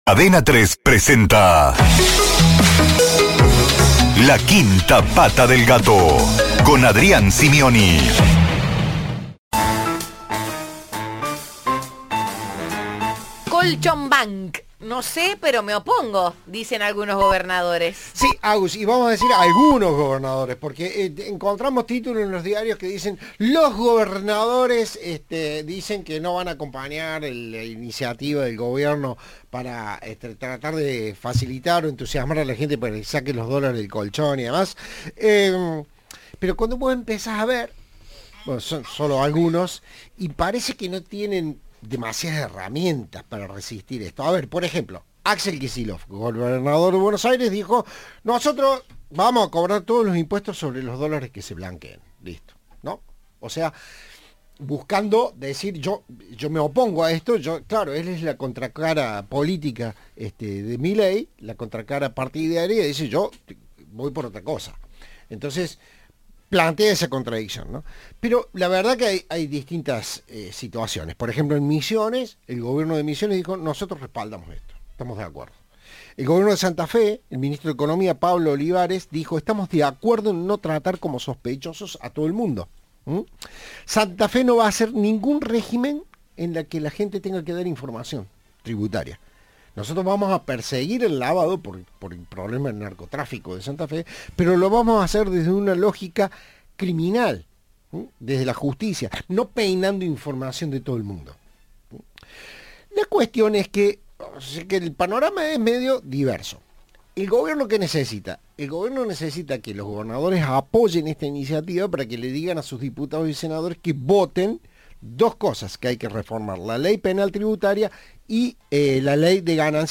El concejal de la ciudad de Córdoba y candidato a senador apuntó, en diálogo con Cadena 3, contra las postulaciones de quienes, en el caso de ganar, no terminarían de cumplir el periodo de su cargo actual.